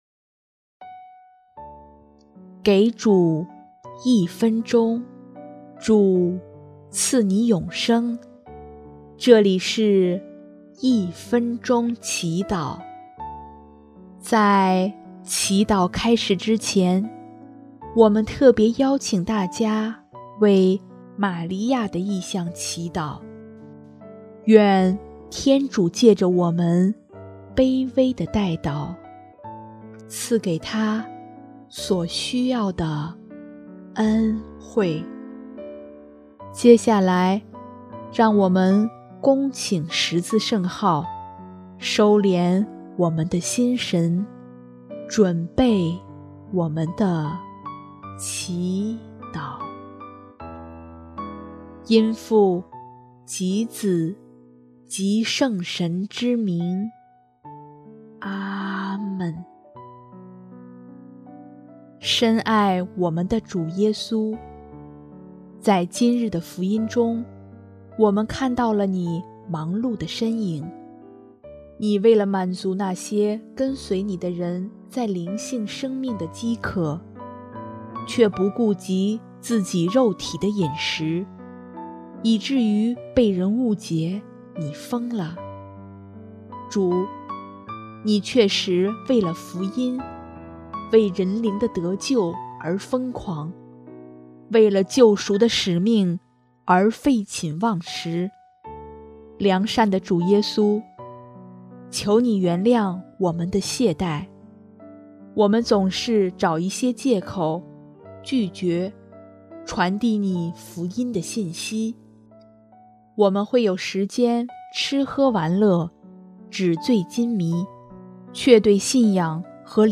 【一分钟祈祷】|1月20日 分一些时间给主！